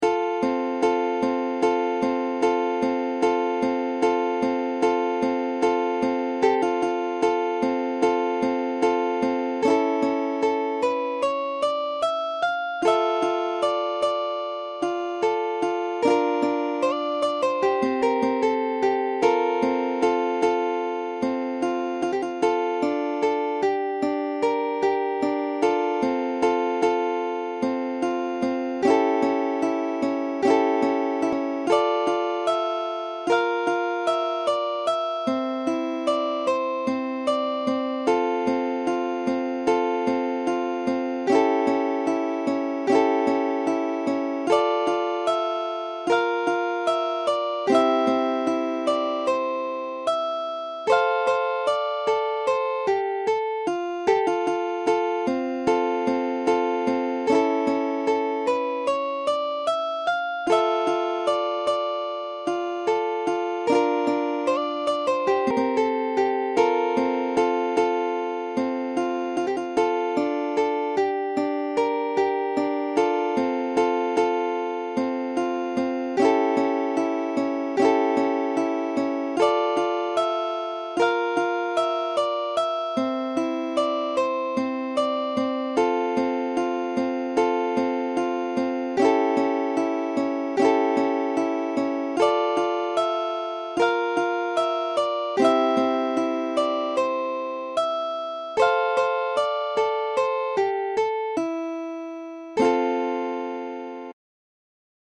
Ukulele Fingerstyle Solo Tab 乌克丽丽 指弹 独奏 谱